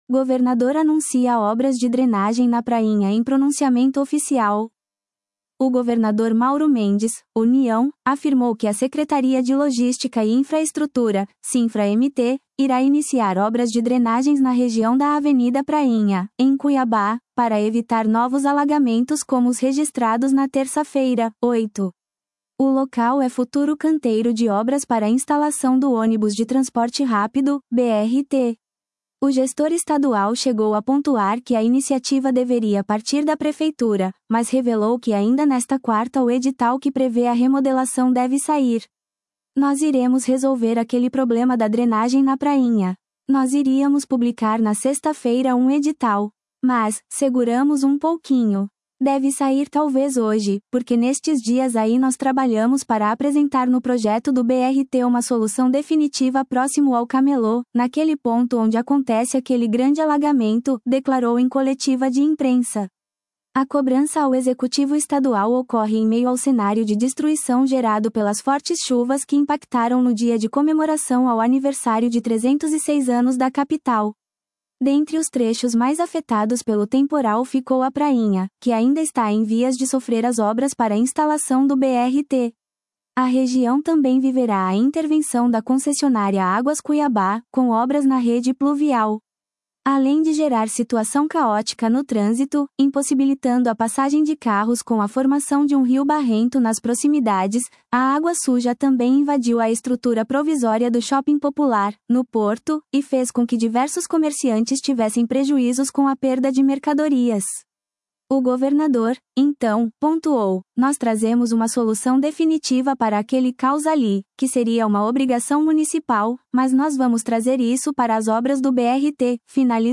Governador anuncia obras de drenagem na Prainha em pronunciamento oficial
“Nós iremos resolver aquele problema da drenagem na Prainha. Nós iríamos publicar na sexta-feira um edital. Mas, seguramos um pouquinho. Deve sair talvez hoje, porque nestes dias aí nós trabalhamos para apresentar no projeto do BRT uma solução definitiva próximo ao camelô, naquele ponto onde acontece aquele grande alagamento”, declarou em coletiva de imprensa.